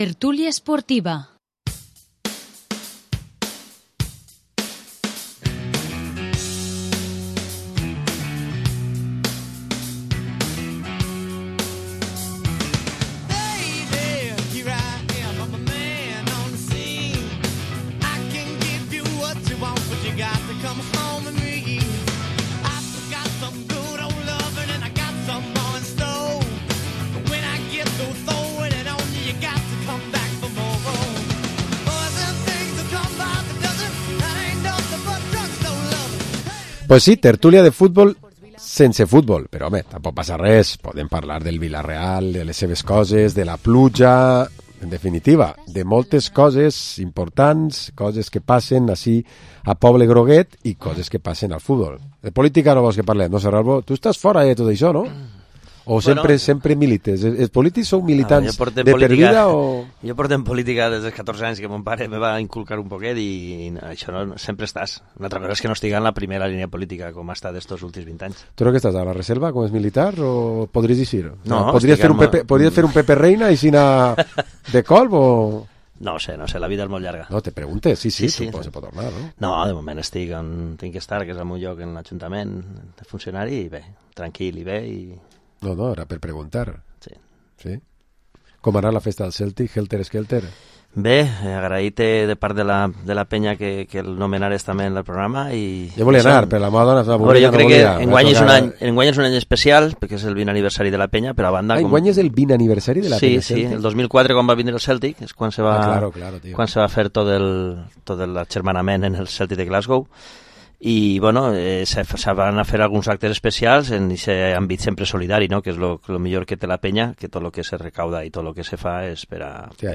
Programa esports tertúlia dilluns 25 de març